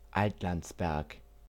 Altlandsberg (German: [ˈaltlantsˌbɛʁk]
Altlandsberg.ogg.mp3